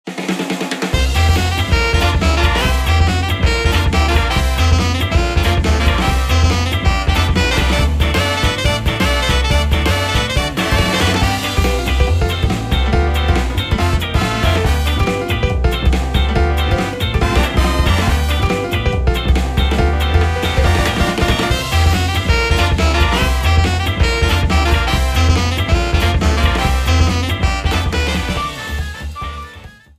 Shortened, applied fade-out and converted to oga
Fair use music sample